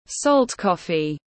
Cà phê muối tiếng anh gọi là salt coffee, phiên âm tiếng anh đọc là /sɒlt ˈkɒf.i/
Salt coffee /sɒlt ˈkɒf.i/